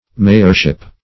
Mayorship \May"or*ship\, n. The office of a mayor.